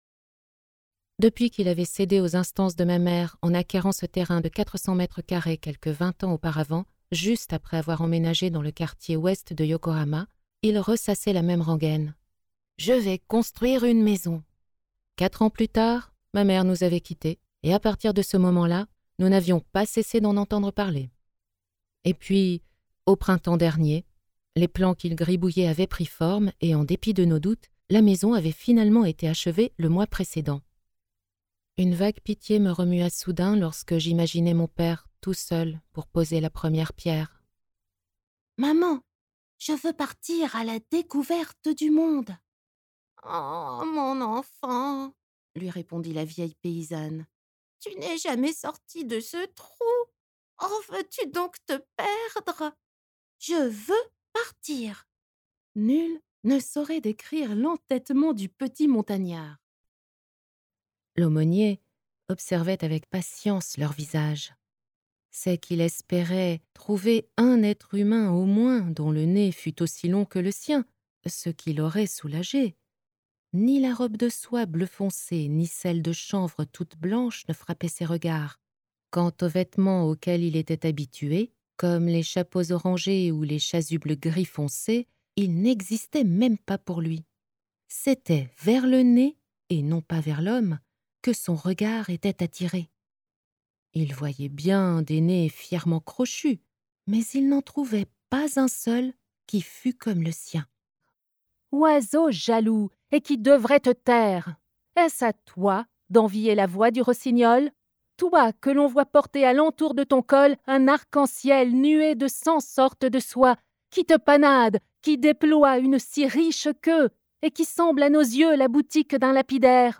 Voix off
NARRATION - AUDIOBOOKS
6 - 100 ans - Mezzo-soprano